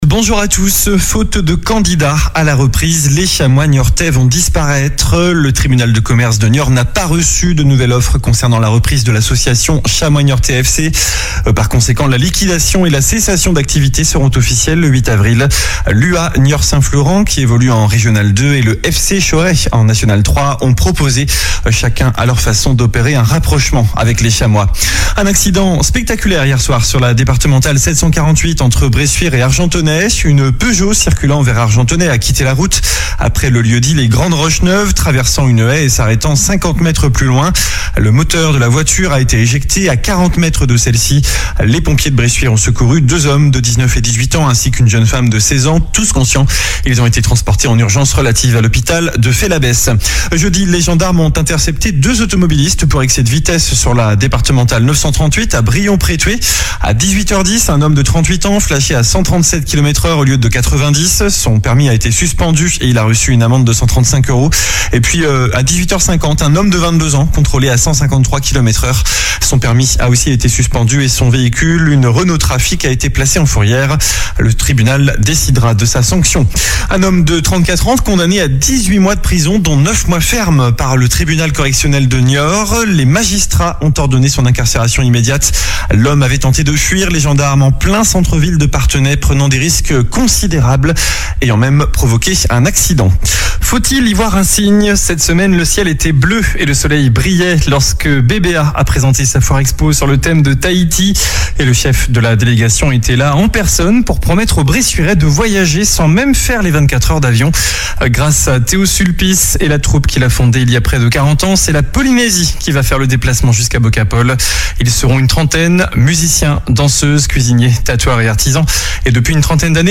Journal du samedi 22 mars